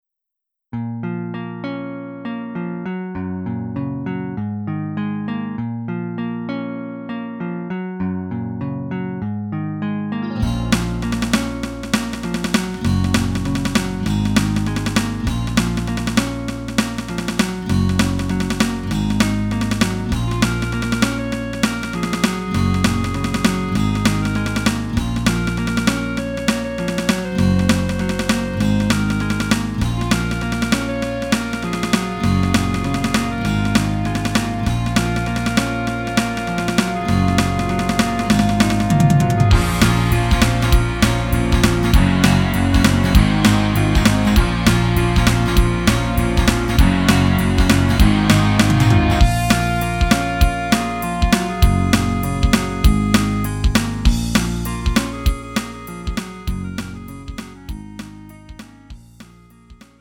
음정 원키 3:57
장르 pop 구분 Lite MR